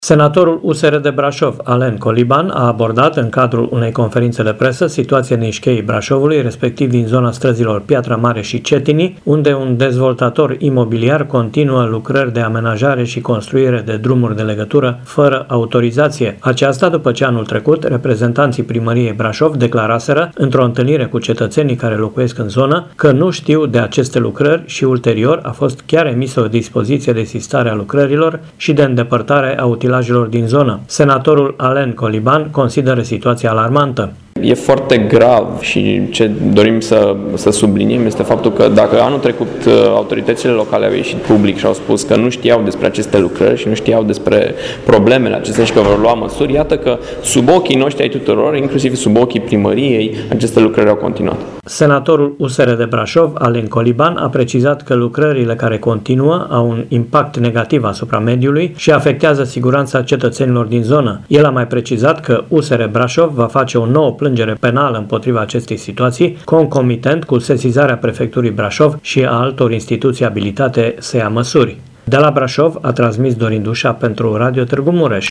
Senatorul USR de Brașov, Allen Coliban, a abordat, în cadrul unei conferințe de presă, situația din Scheii Brașovului, respectiv din zona străzilor Piatra Mare și Cetinii.
Senatorul Allen Coliban consideră situația alarmantă: